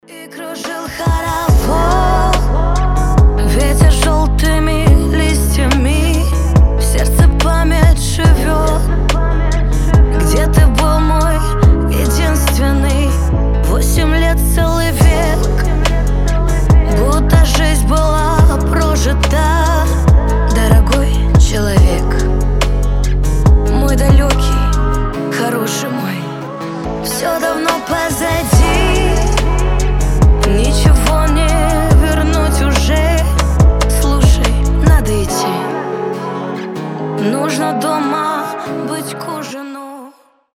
• Качество: 320, Stereo
грустные
женский голос